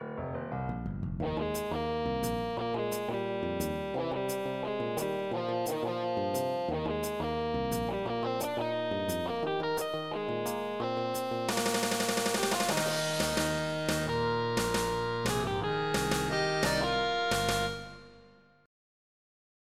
MIDI Composition
They are short and experimental.
This was created to be the intro to a driving song, perhaps inspired by punk, rock, metal...